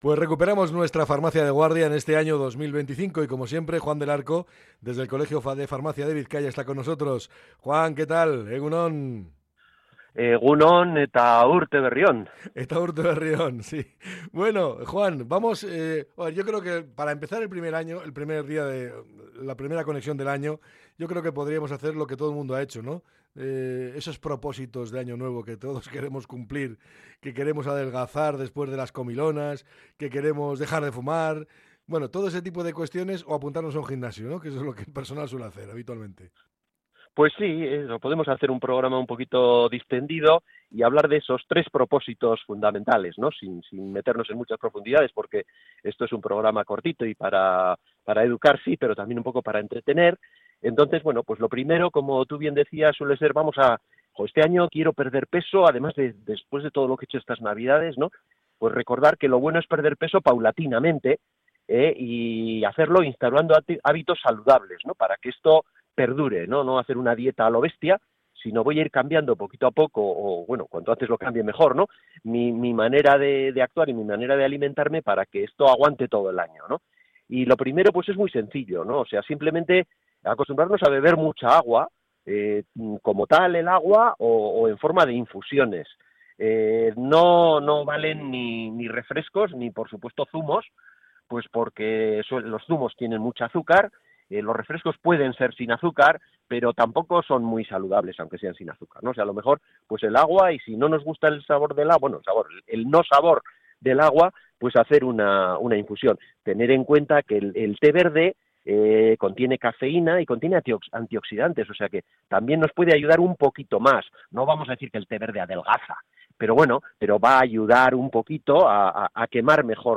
la charla